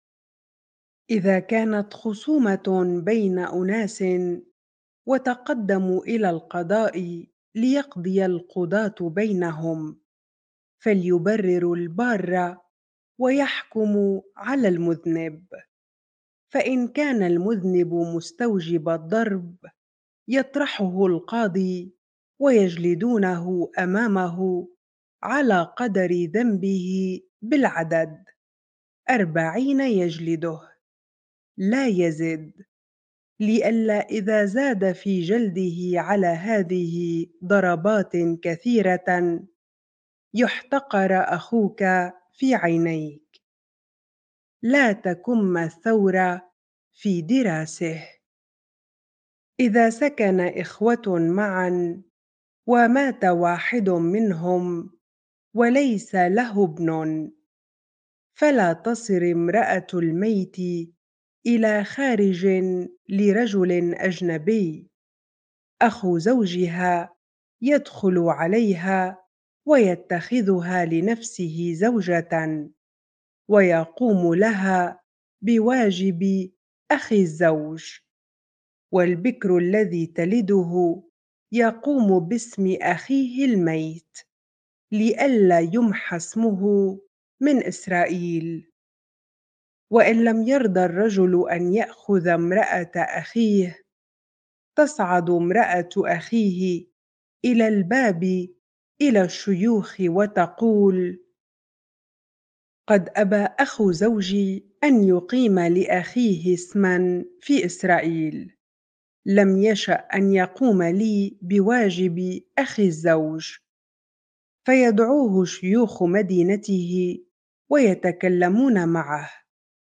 bible-reading-deuteronomy 25 ar